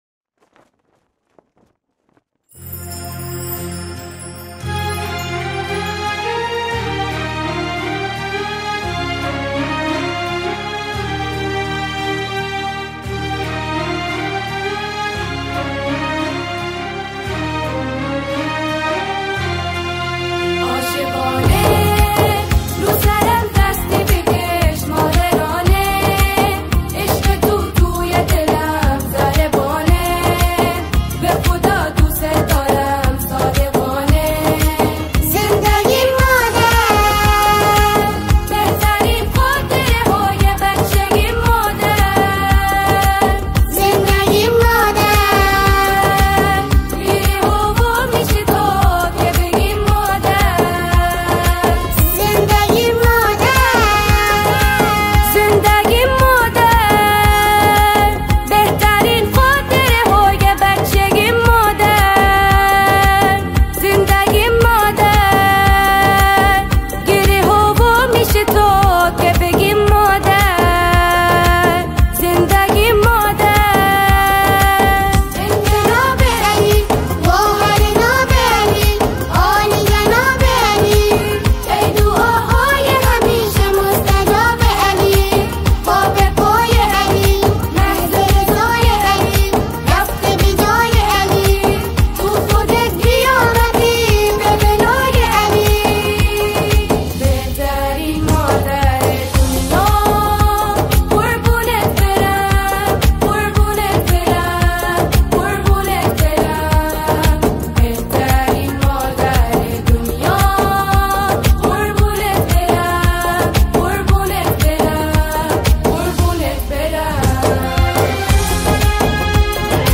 نماهنگ زیبا و احساسی